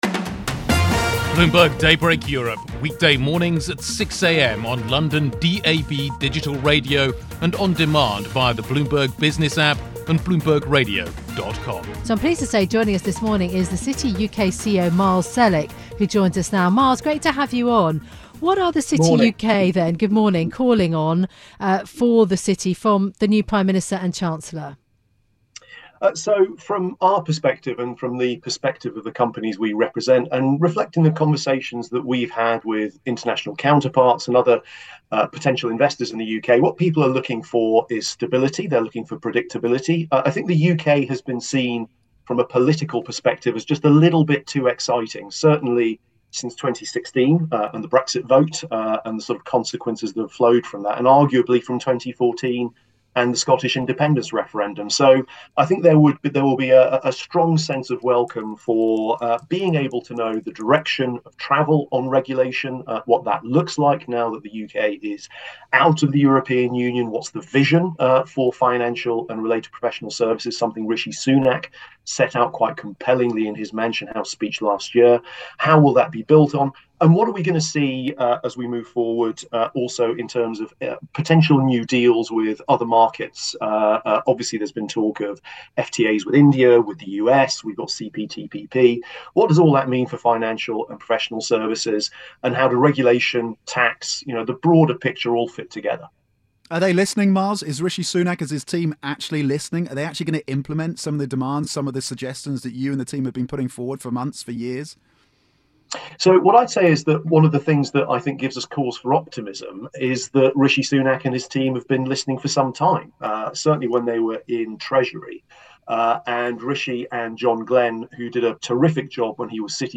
on Bloomberg radio